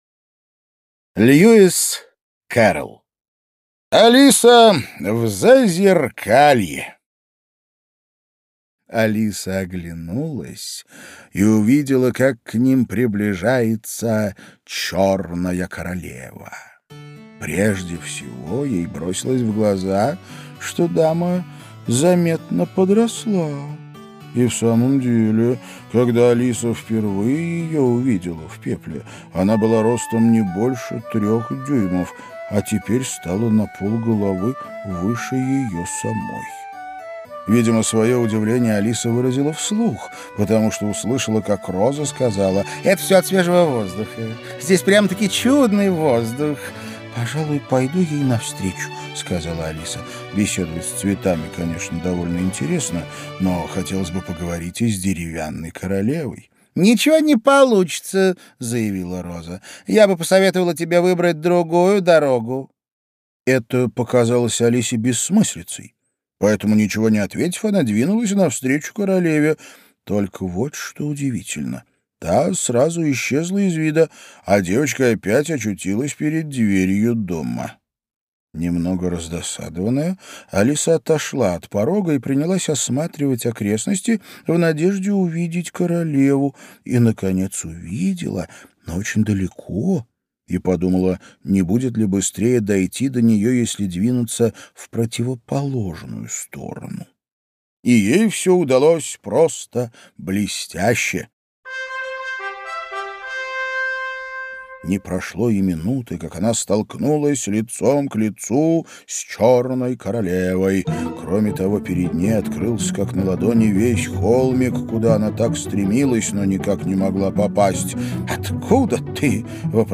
Аудиокнига Алиса в Зазеркалье | Библиотека аудиокниг